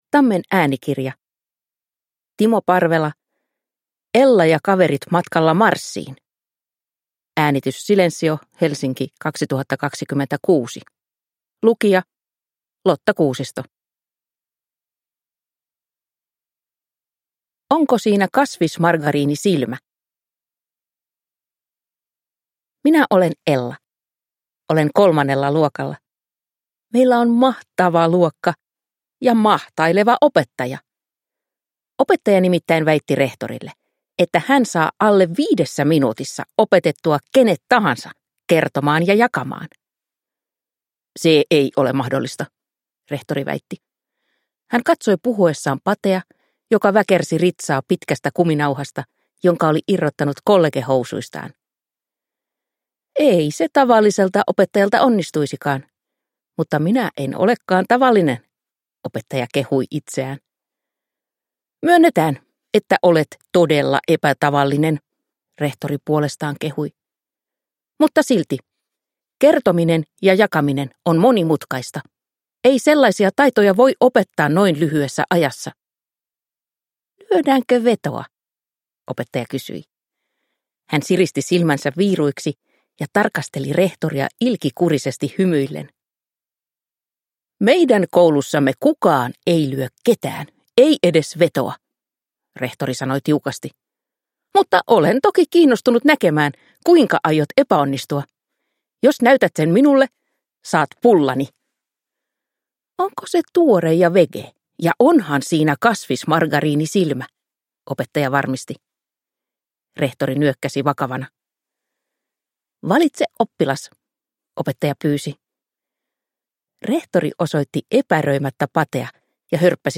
Ella ja kaverit matkalla Marsiin – Ljudbok